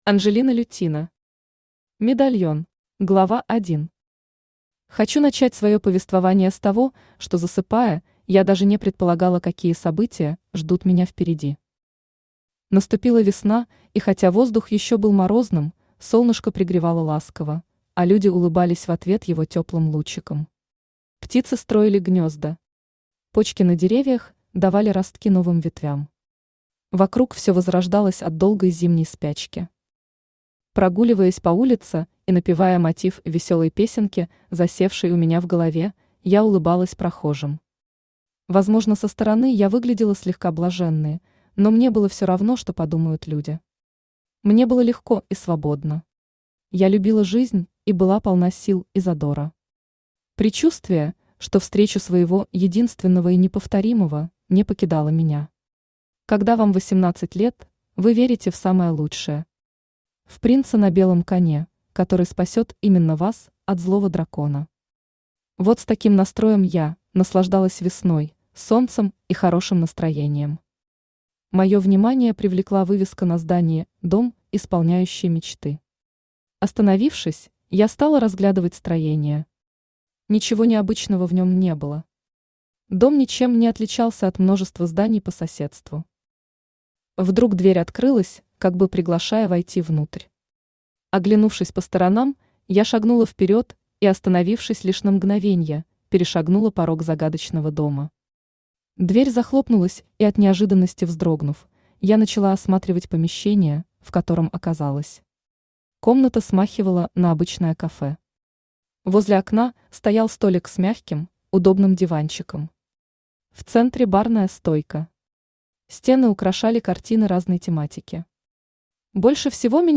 Аудиокнига Медальон | Библиотека аудиокниг
Aудиокнига Медальон Автор Анжелина Ивановна Лютина Читает аудиокнигу Авточтец ЛитРес.